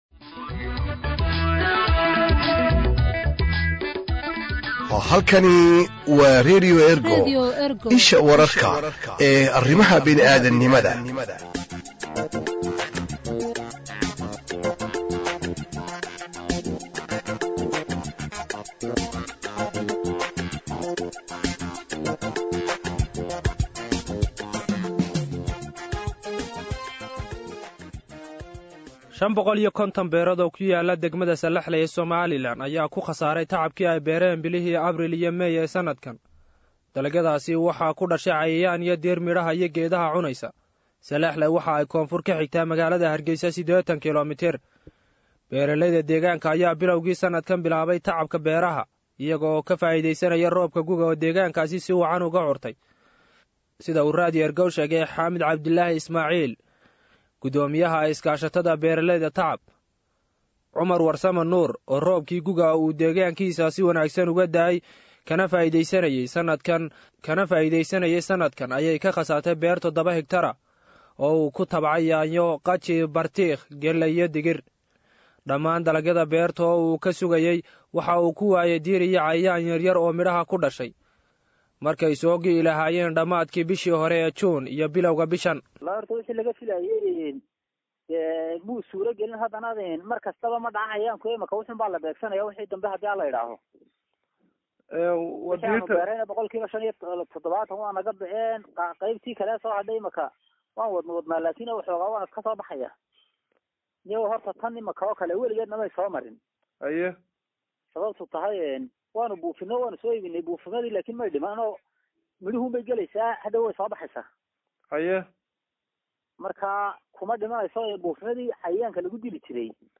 WEBKA-WARBIXIN-BEERO.mp3